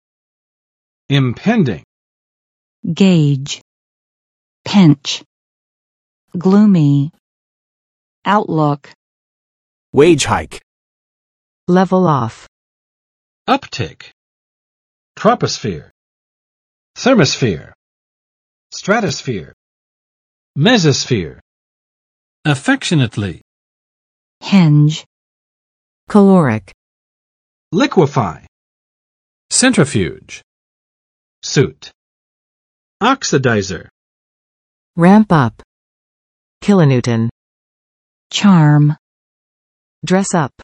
[ɪmˋpɛndɪŋ] adj. 即将发生的；逼近的